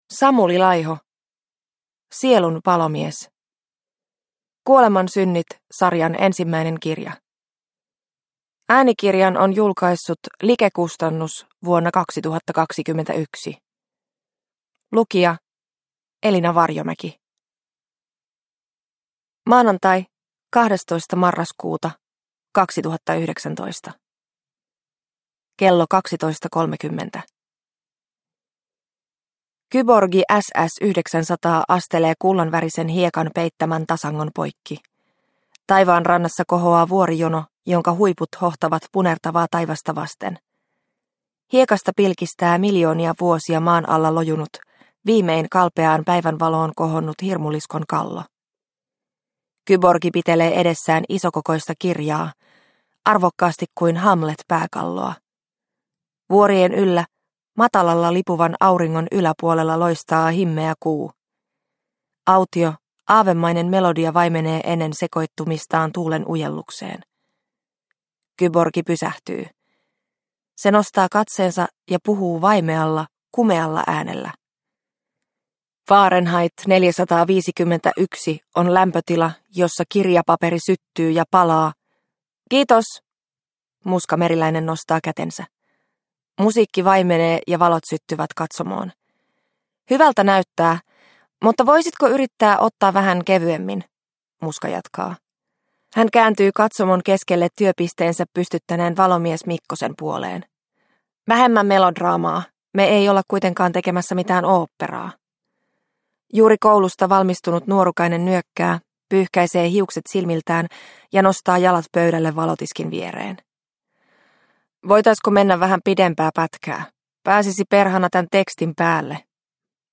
Sielun palomies – Ljudbok – Laddas ner